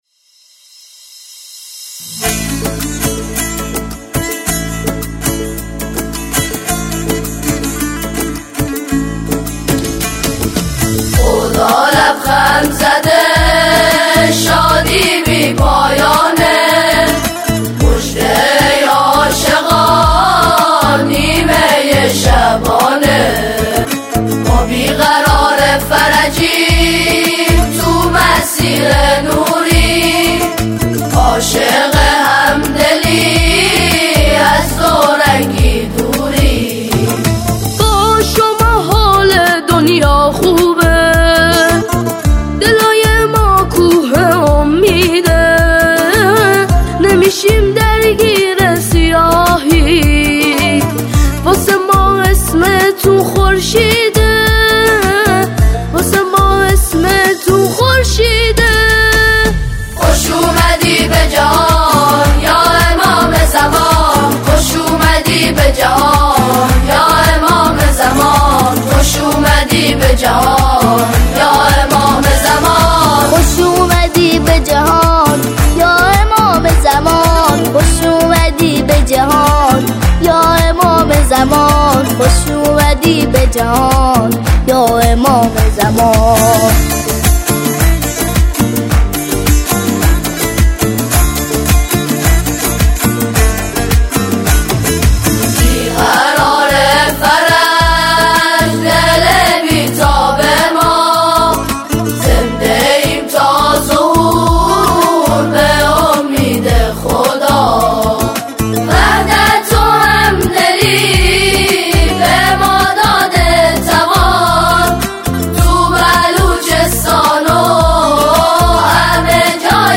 آنها در این قطعه، شعری را درباره نیمه شعبان همخوانی می‌کنند.